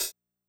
E-mu Drumulator Sample Pack_CHH.wav